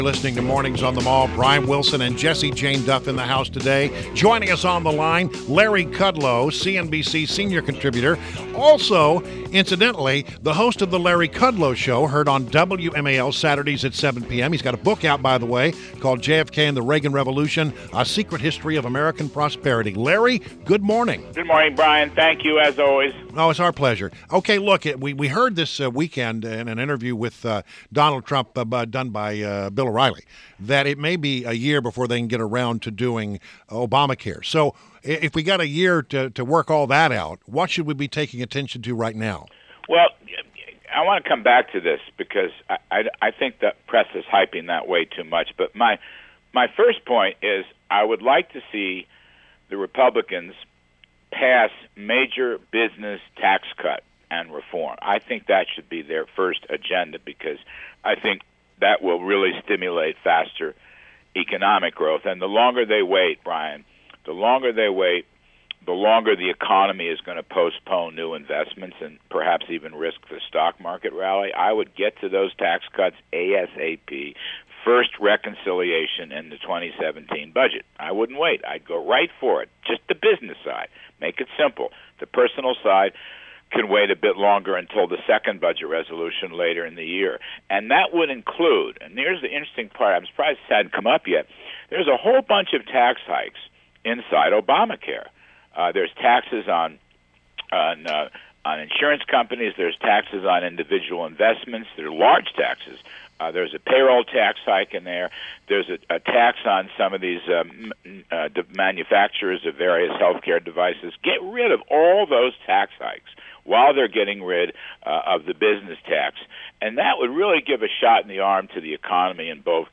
WMAL Interview - LARRY KUDLOW - 02.07.17